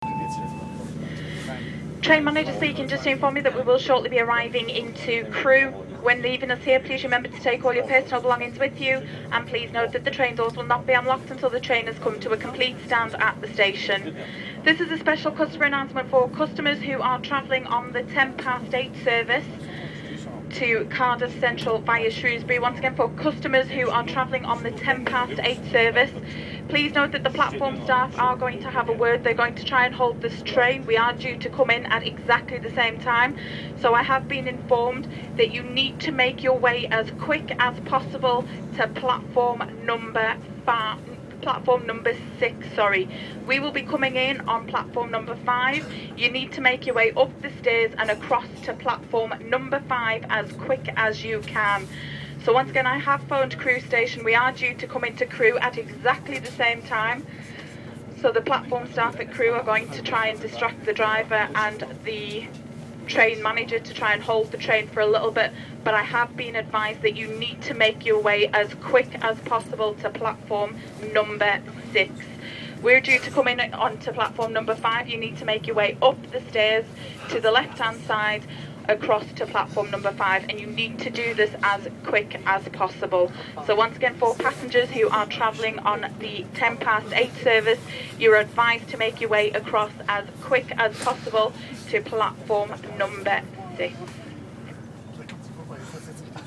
Achtung, Durchsage!
Crewe.mp3